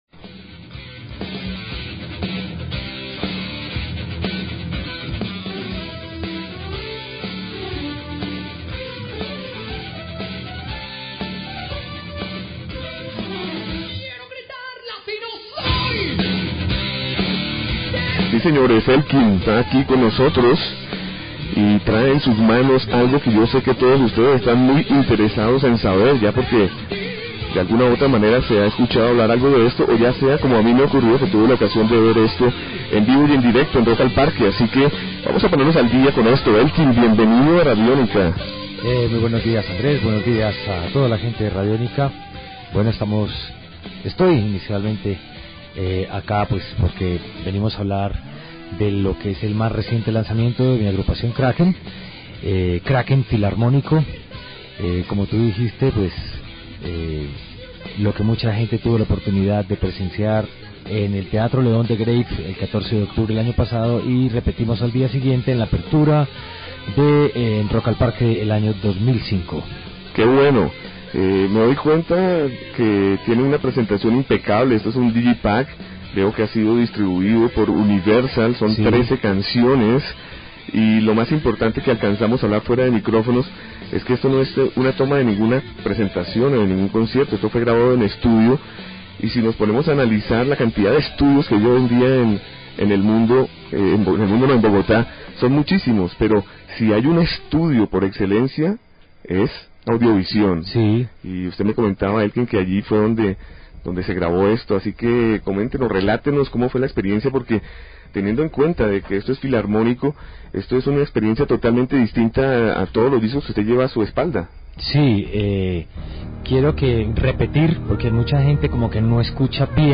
En una entrevista de 2014 para la emisora Radiónica su vocalista, Elkin Ramírez, contó su historia.
entrevistakraken (1).mp3